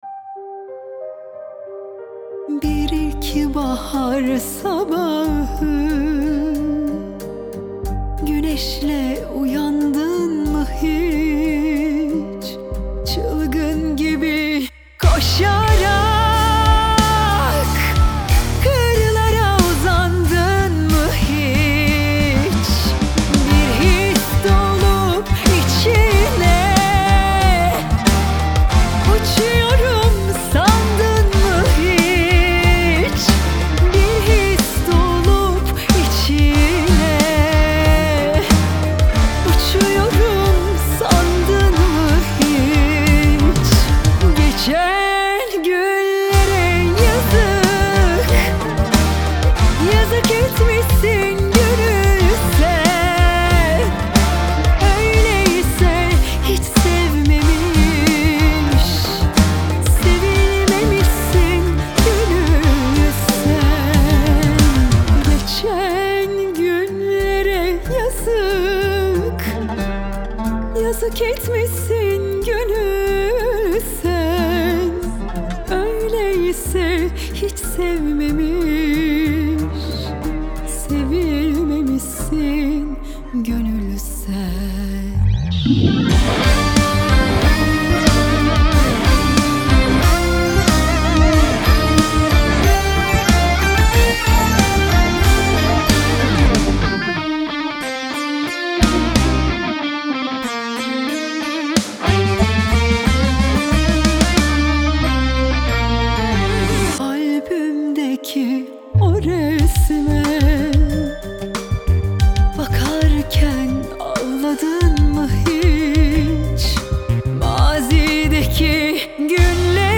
Трек размещён в разделе Турецкая музыка / Поп.